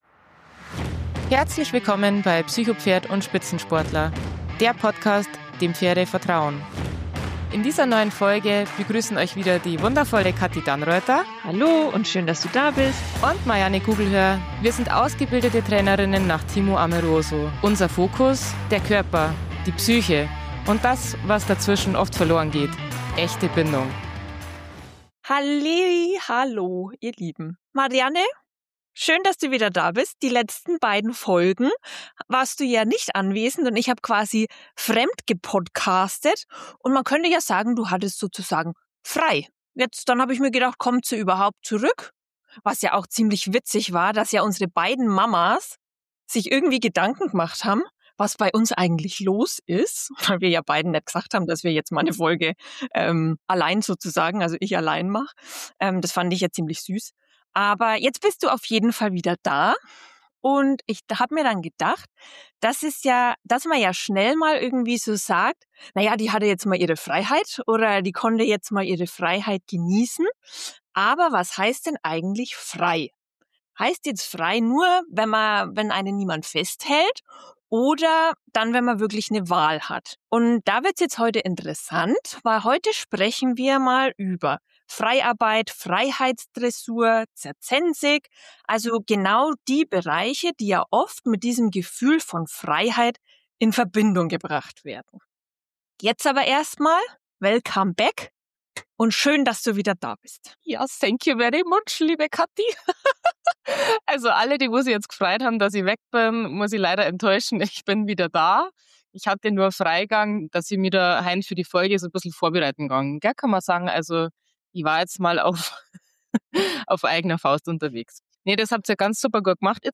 Freut euch auf ein tiefgehendes, ehrliches Gespräch über Stress, erlernte Hilflosigkeit und die wahren Grundlagen für eine gesunde Beziehung zwischen Mensch und Pferd.